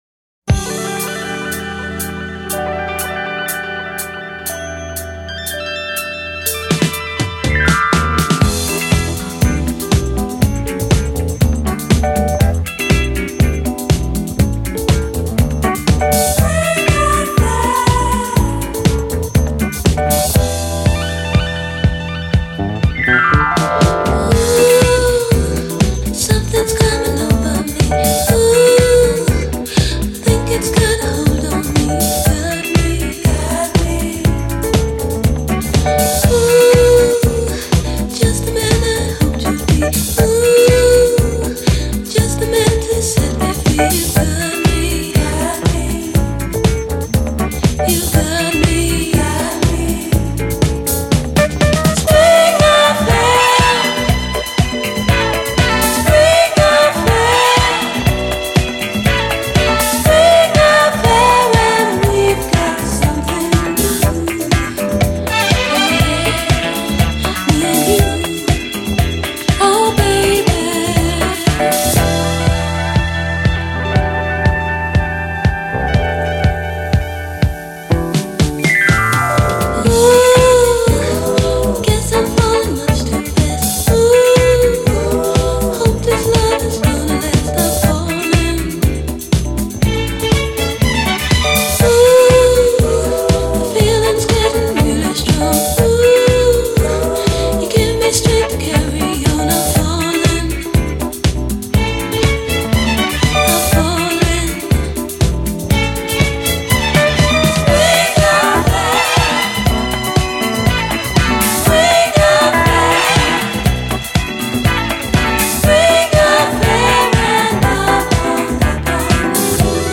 Кому интересно что за KEYBOARD Клавишник фантастический либо крут чел предложивший конкретные точки; неотвратимое движение Вложения DONNA SUM 21 KEYBOARD.mp3 DONNA SUM 21 KEYBOARD.mp3 5,6 MB · Просмотры: 289